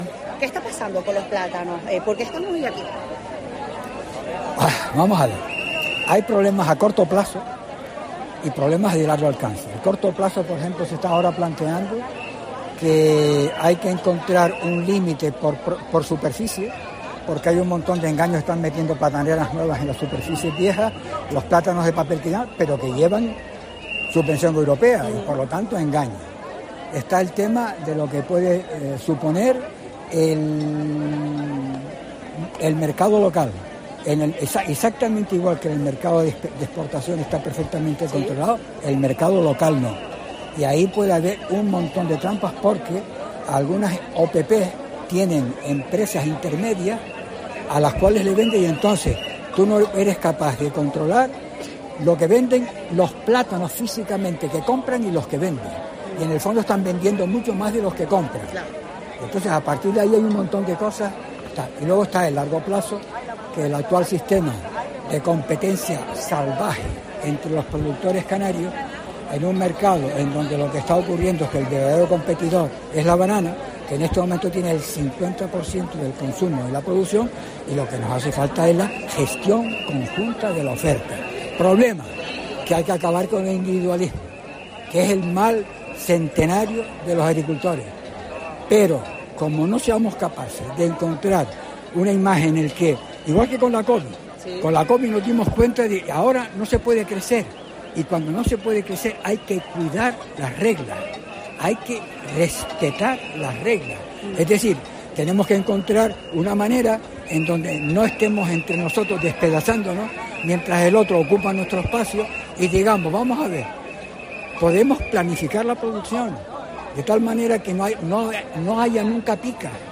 Entrevista
Herrera en COPE Canarias ha estado hoy presente en la tractorada organizada por diferentes cooperativas del sector primario.